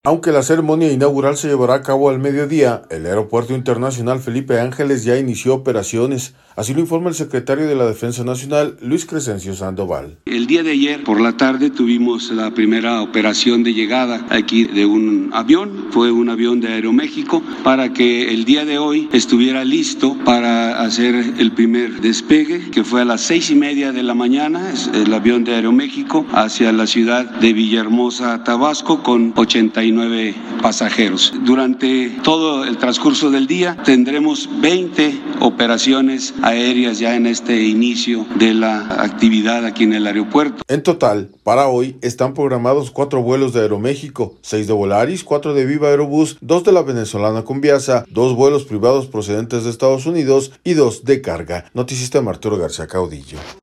Aunque la ceremonia inaugural se llevará a cabo al mediodía, el Aeropuerto Internacional Felipe Ángeles ya inició operaciones, así lo informa el secretario de la Defensa Nacional, Luis Crescencio Sandoval.